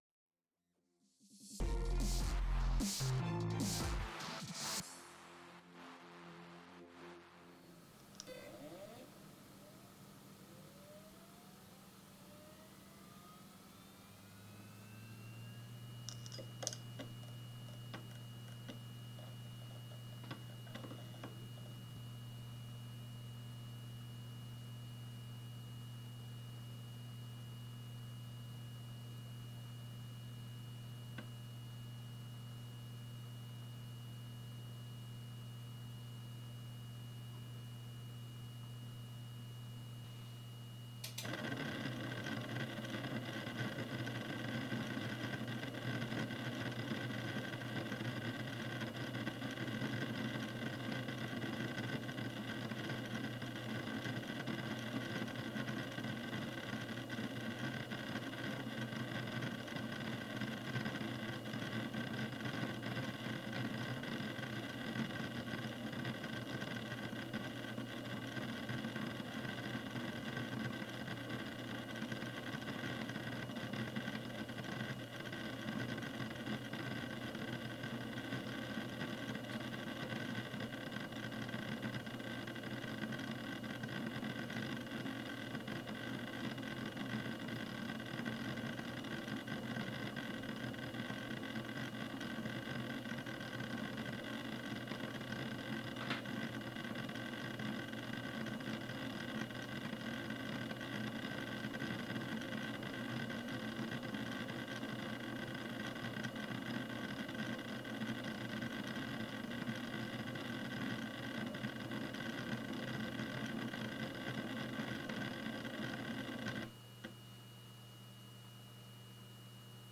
HDD noise levels table/list